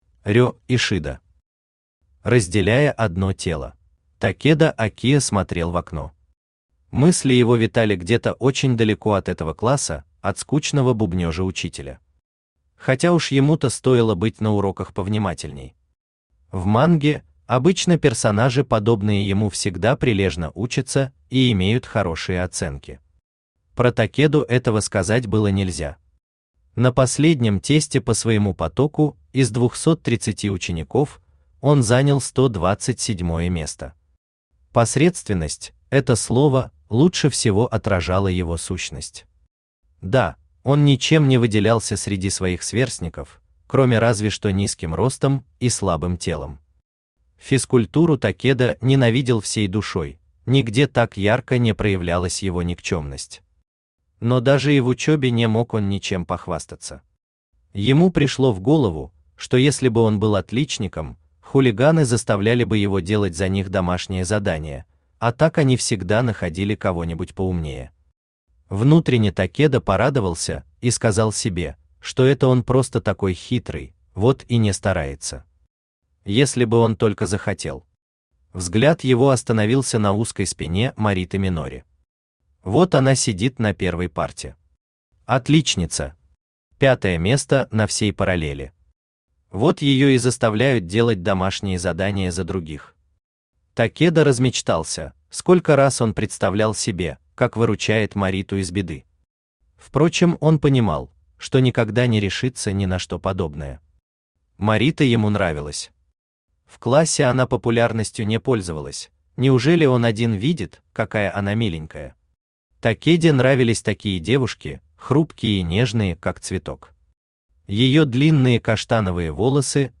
Аудиокнига Разделяя одно тело | Библиотека аудиокниг
Aудиокнига Разделяя одно тело Автор Рё Ишида Читает аудиокнигу Авточтец ЛитРес.